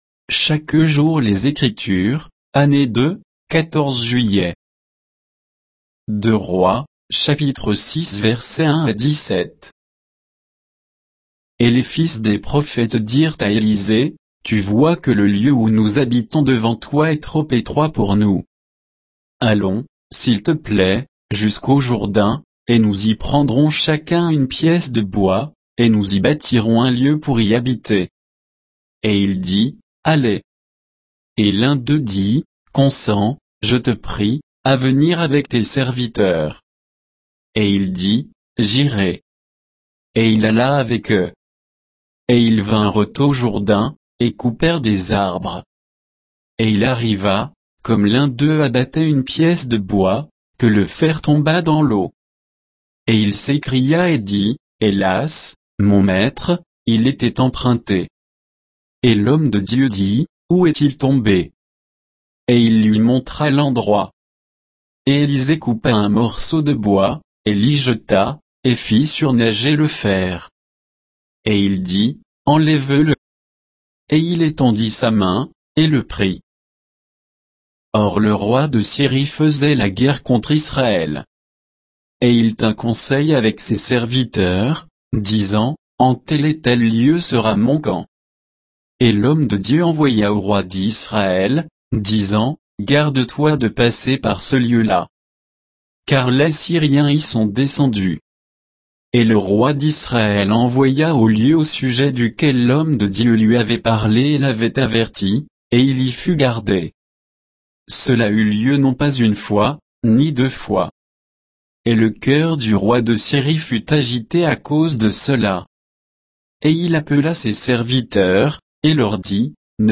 Méditation quoditienne de Chaque jour les Écritures sur 2 Rois 6, 1 à 17